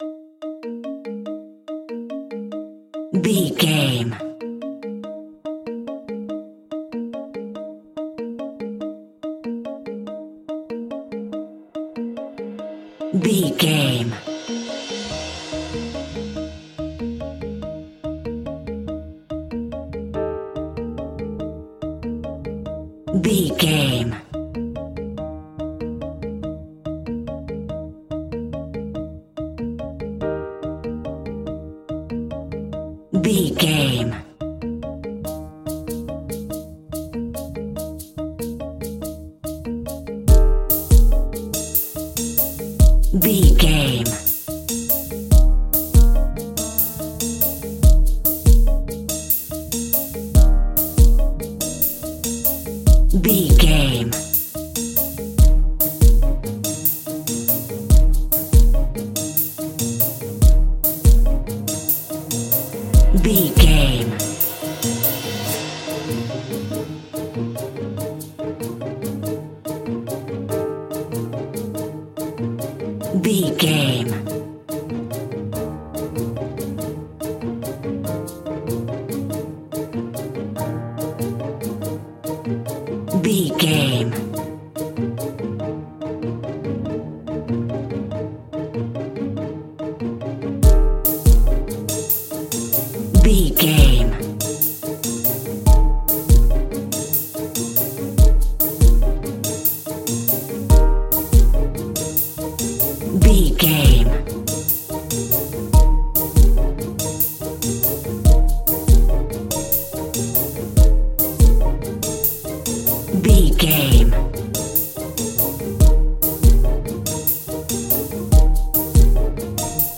Ionian/Major
E♭
percussion
synthesiser
piano
strings
circus
goofy
comical
cheerful
perky
Light hearted
quirky